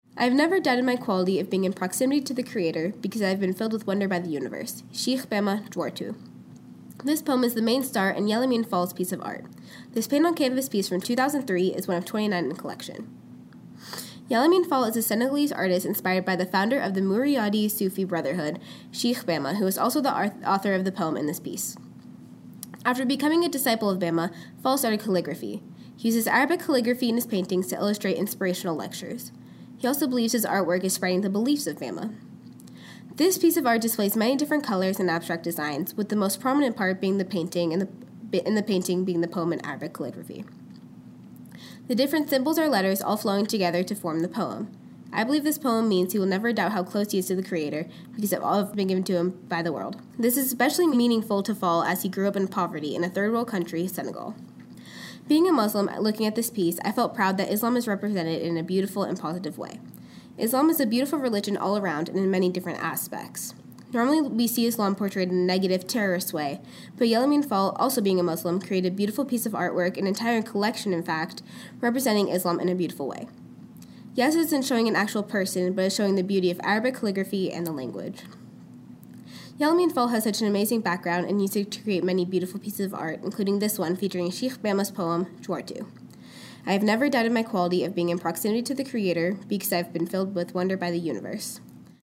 Audio Tour – Bulldog Art Tour